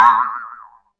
Boing Spring
A classic cartoon spring boing with exaggerated wobble and comedic bounce
boing-spring.mp3